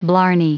Prononciation du mot blarney en anglais (fichier audio)
Prononciation du mot : blarney